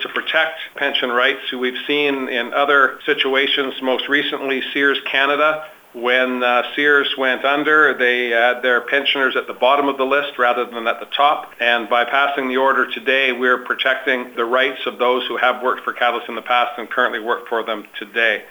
BC Premier John Horgan explains what this announcement ultimately means.